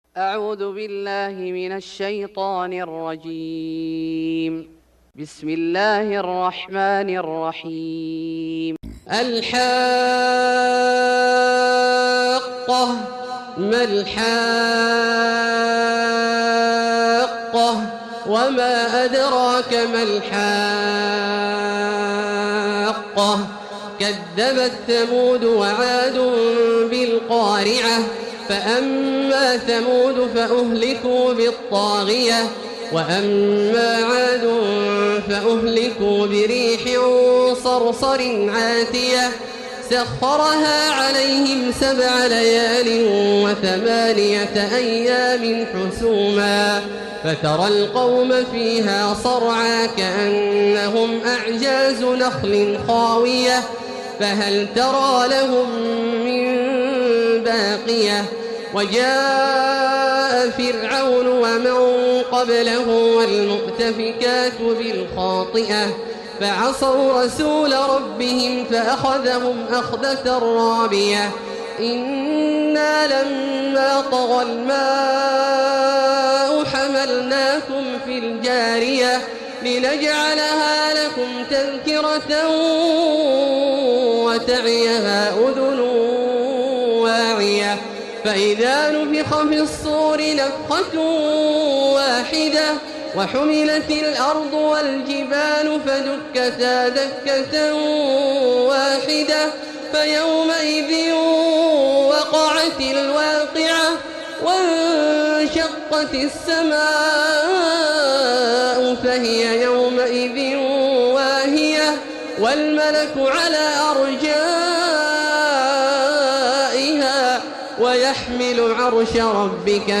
سورة الحاقة Surat Al-Haqqa > مصحف الشيخ عبدالله الجهني من الحرم المكي > المصحف - تلاوات الحرمين